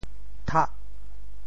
潮州拼音“tah8”的详细信息
thah8.mp3